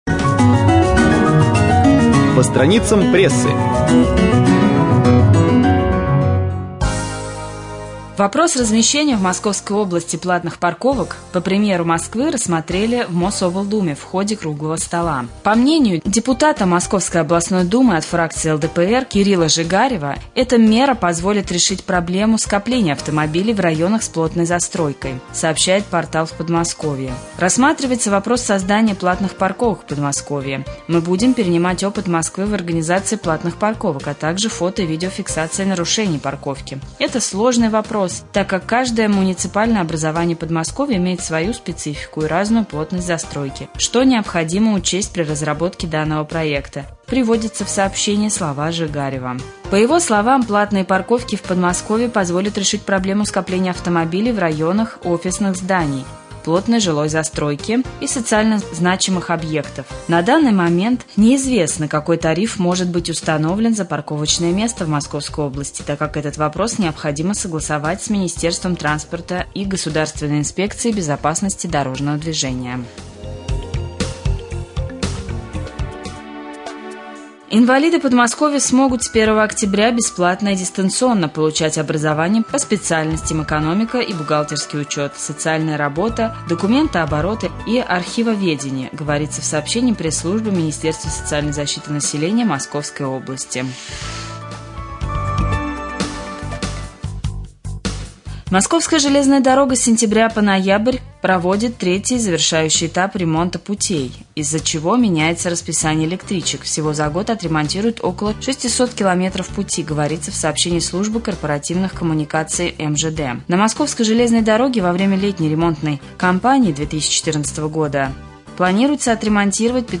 1.Рубрика «По страницам прессы» Новости
1.Новости4.mp3